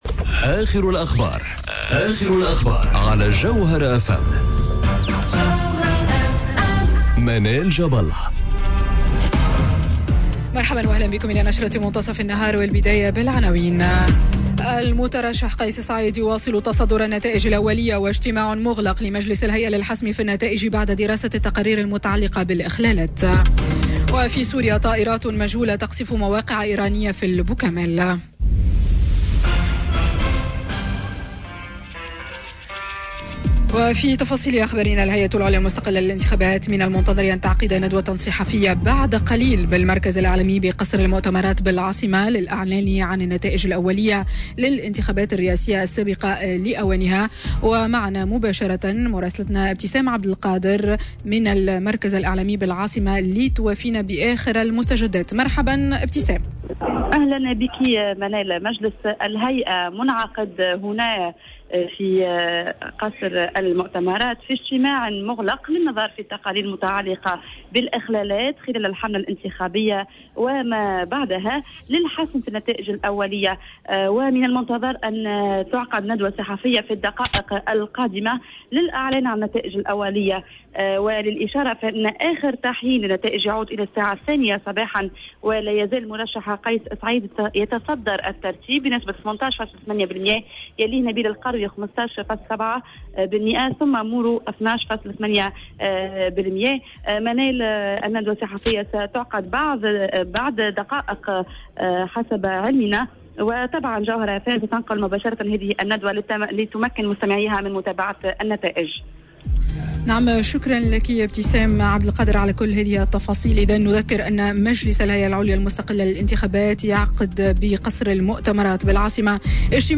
نشرة أخبار منتصف النهار ليوم الثلاثاء 17 سبتمبر 2019